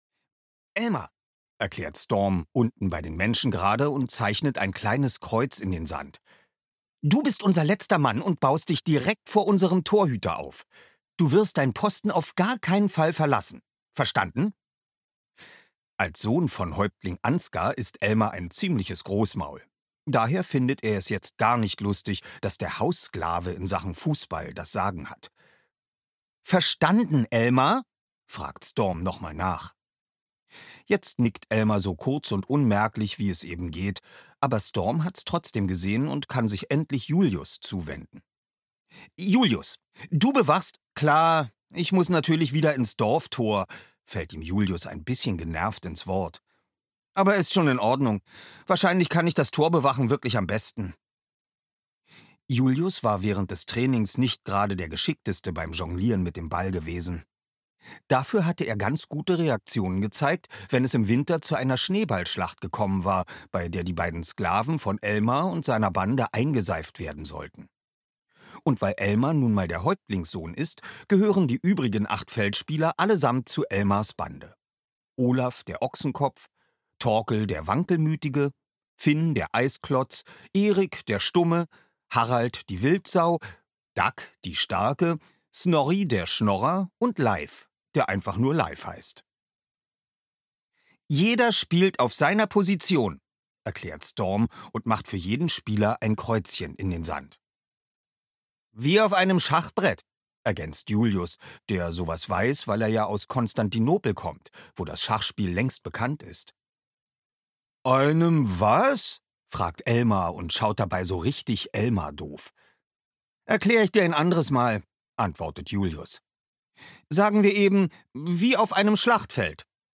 Storm und die Fußballgötter - Jan Birck - Hörbuch